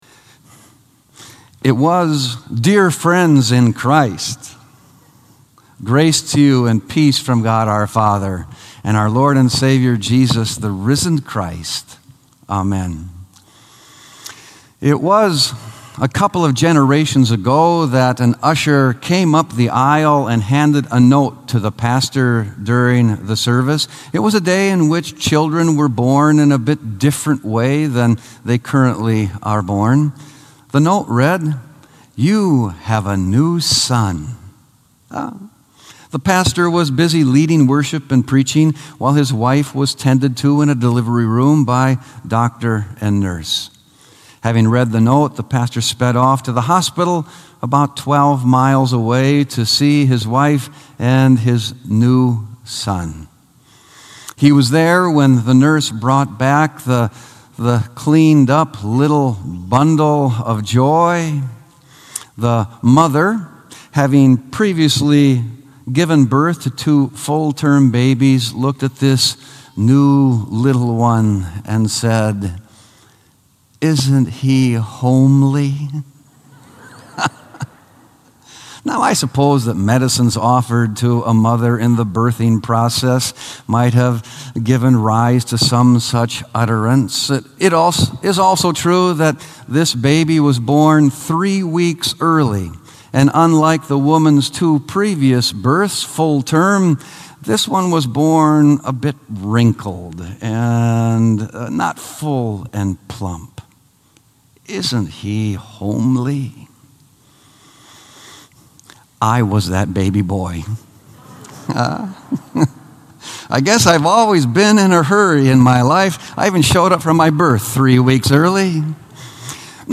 Sermon “It Is All A Gift”